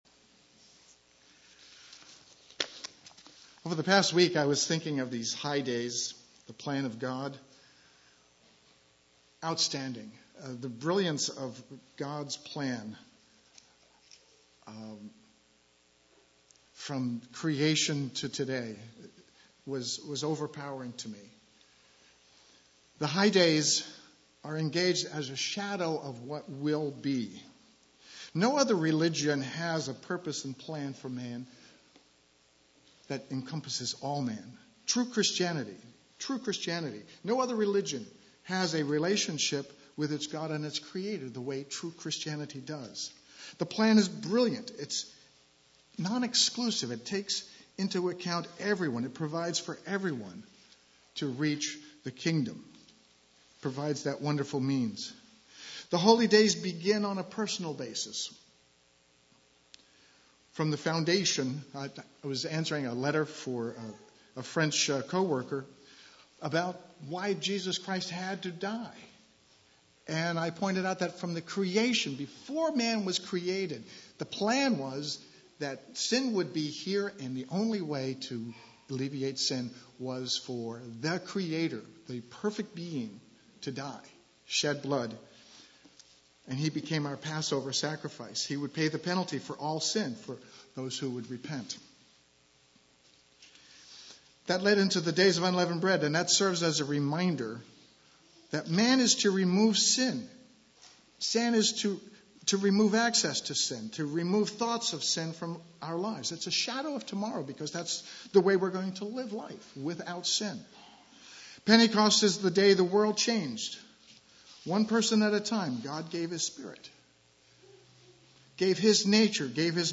Given in Eureka, CA
UCG Sermon Studying the bible?